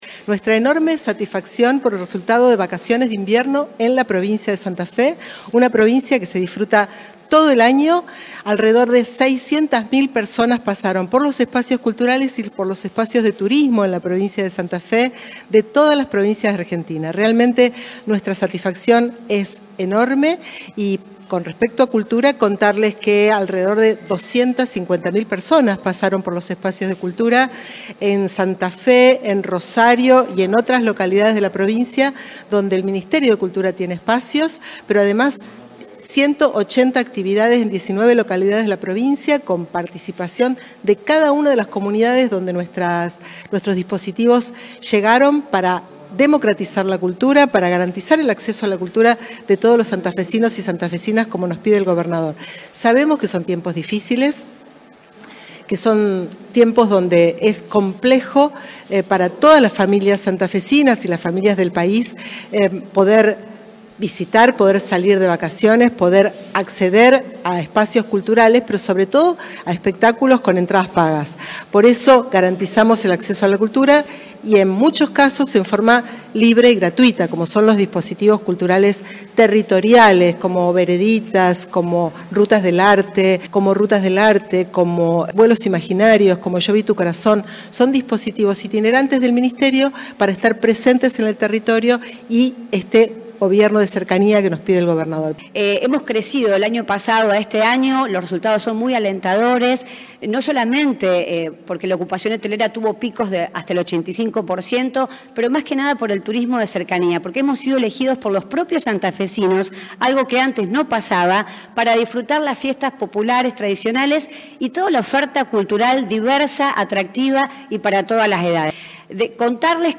El informe se dio a conocer este lunes en el Auditorio de Casa de Gobierno.
La ministra de Cultura, Susana Rueda, y la secretaria de Turismo, Marcela Aeberhard.